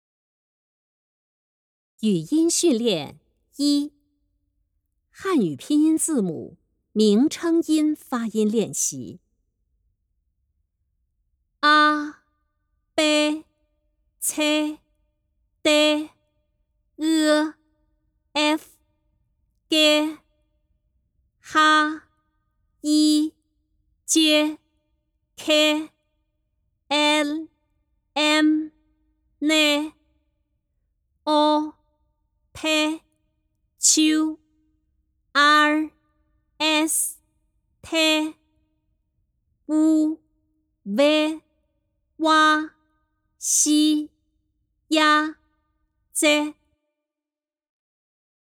第三讲语音训练一-女声